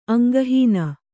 ♪ aŋgahīna